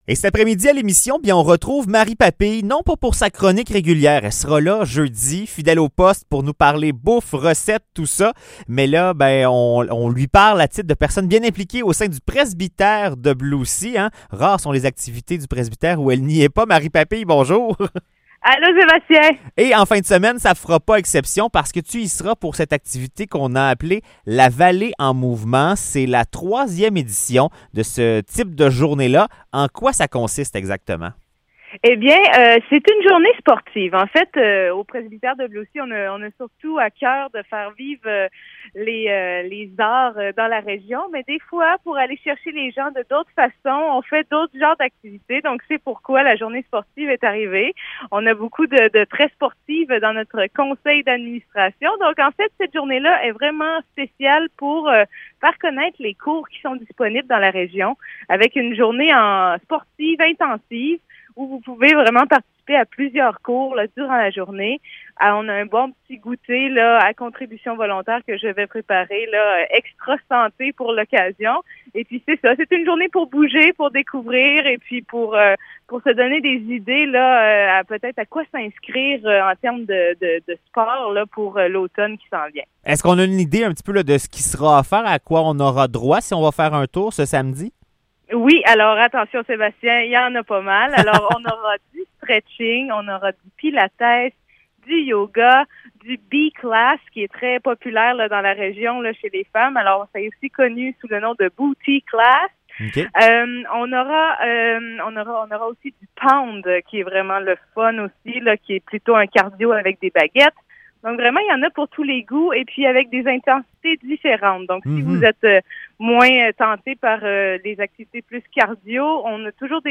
Entrevue sur l'événement La Vallée en mouvement
entrevue-sur-levenement-la-vallee-en-mouvement.mp3